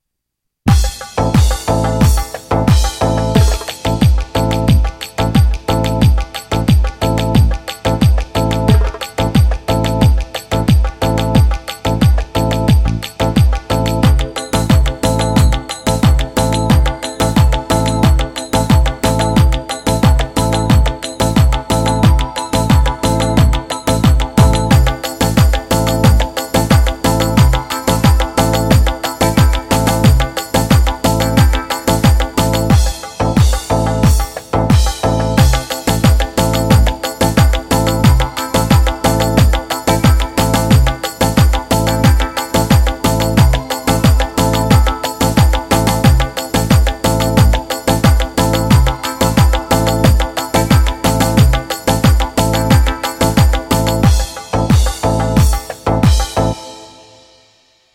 ketron xd9 ritim yazarken
ritim yazarken ataklarını henüz yazmadığım bir ritim